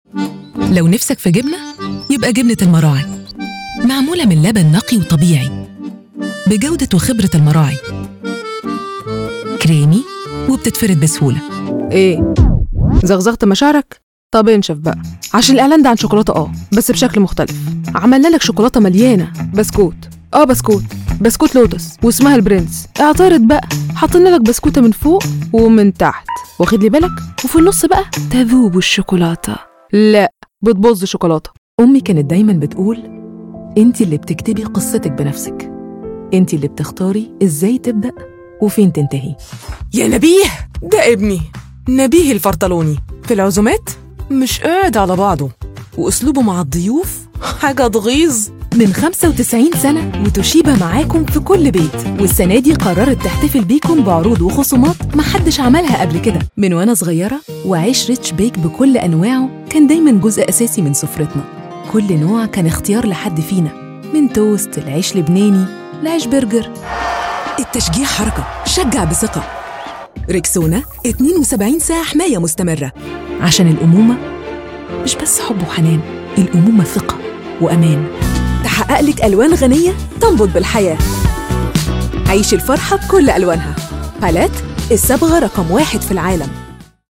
A bilingual professional female voice over artist who provides versatile tones and quality recordings in fast turnaround
New-Commercial-demoreel-Egyptian-accent.mp3